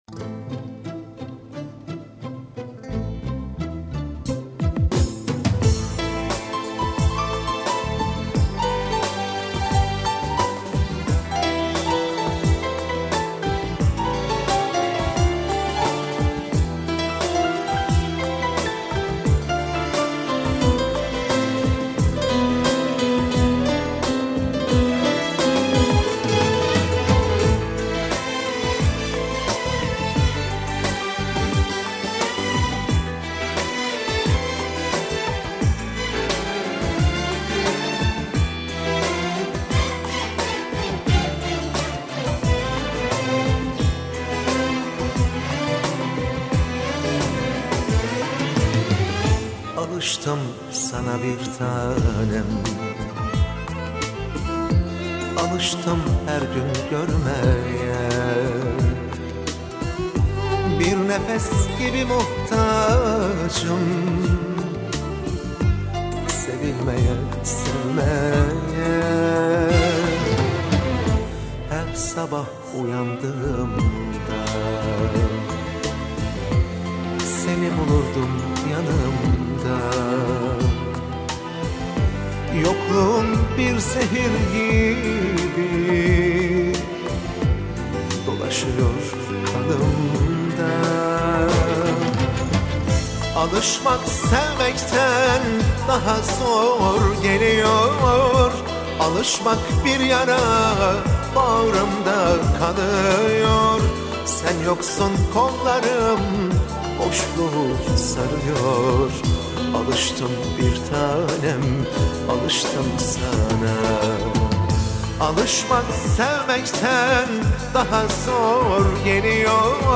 turkish